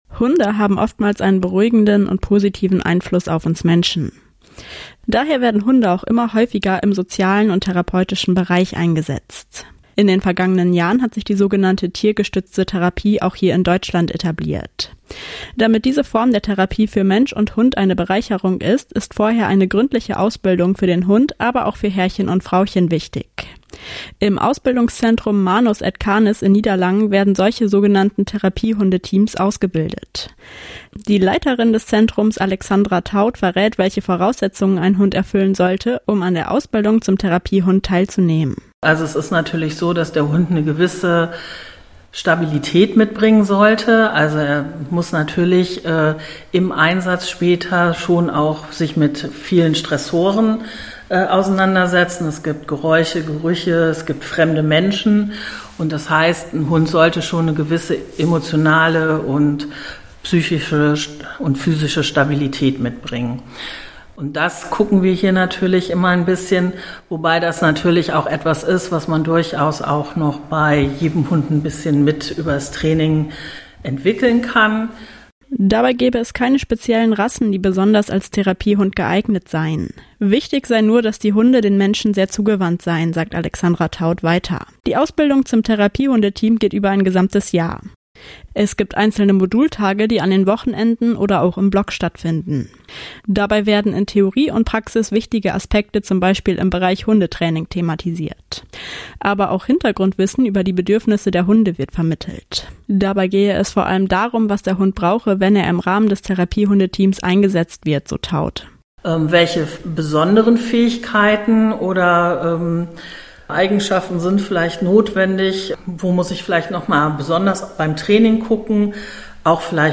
Interview von Radio “Ems-Vechte-Welle”